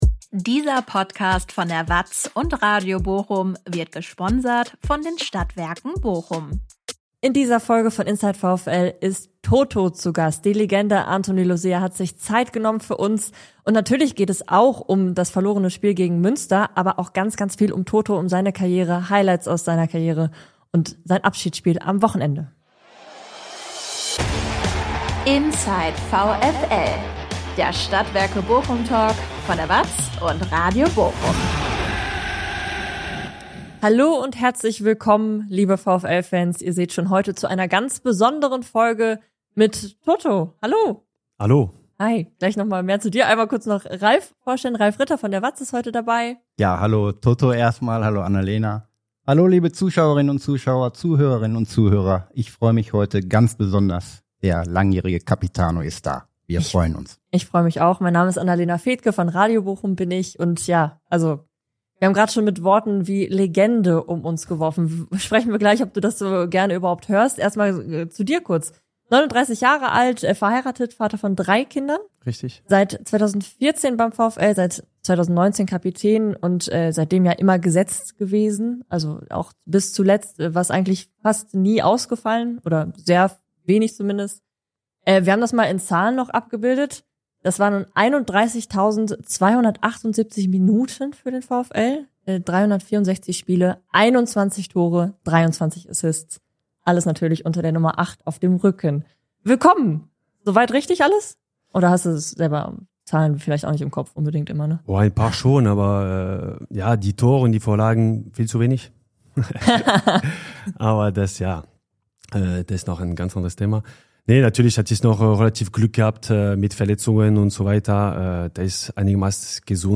Anthony Losilla feiert am kommenden Samstag unter dem Motto „Toto & Friends“ seinen Abschied als Spieler vom VfL Bochum. Die Legende spricht in unserer neuen Talk-Folge über viele Emotionen, die aktuelle Lage, bewegende Momente seiner Karriere, seine Trainer-Laufbahn und die Rolle der Fans.